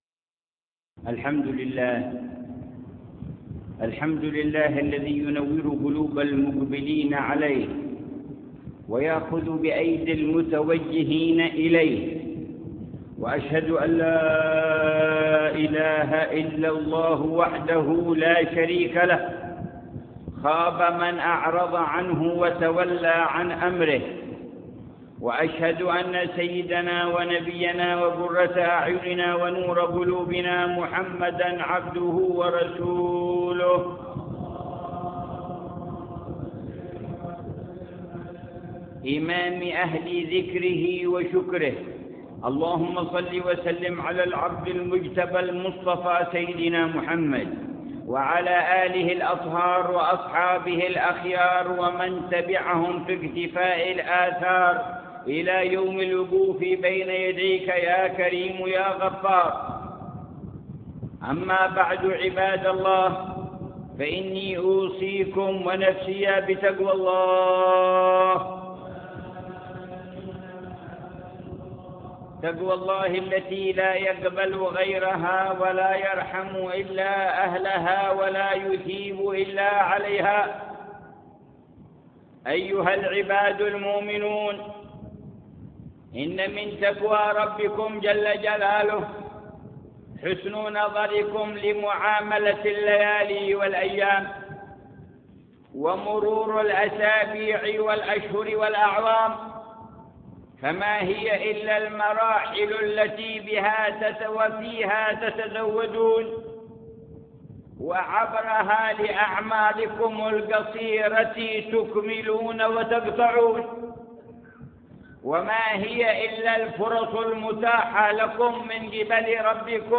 خطبة جمعة للحبيب عمر في جامع الإحسان تريم - حضرموت تاريخ 3 رجب 1430هـ بعنوان: فقه التعامل مع الليالي والأيام.